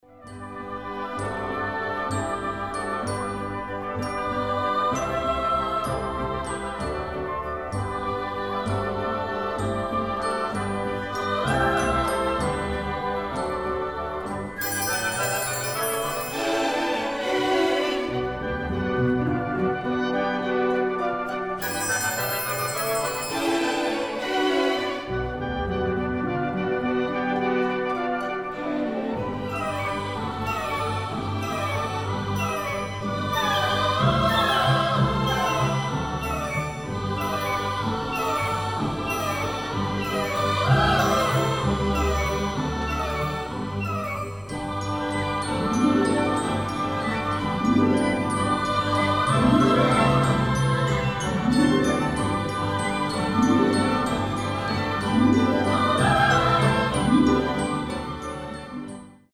• Качество: 320, Stereo
без слов